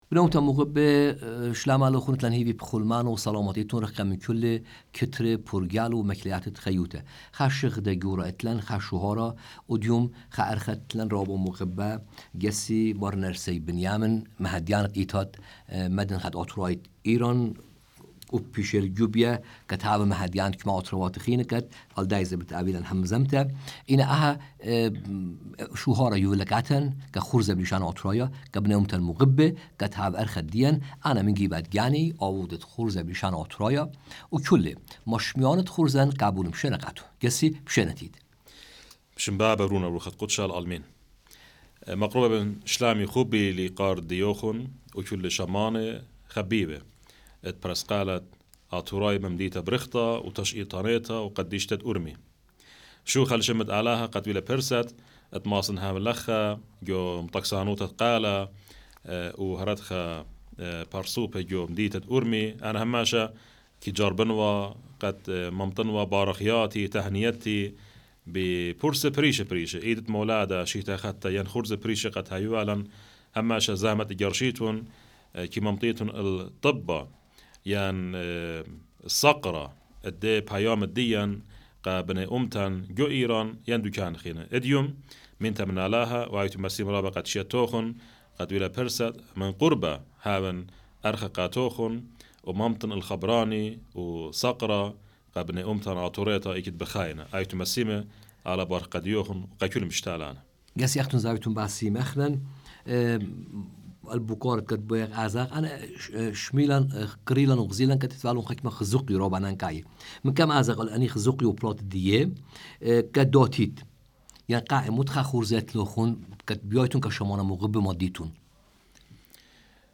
ܚܕܐ ܗܡܙܡܬܐ ܥܡ ܡܪܝ ܢܪܣܝ ܒܢܝܡܝܢ ܬܓܒܪܢܐ ܕܥܕܬܐ ܕܡܕܢܚܐ ܕܐܬܘܪܝܐ ܕܐܝܪܢ